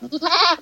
goat02.ogg